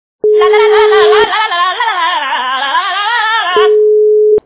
» Звуки » Смешные » ла-ла - ла-ла
При прослушивании ла-ла - ла-ла качество понижено и присутствуют гудки.